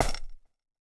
Step4FX.wav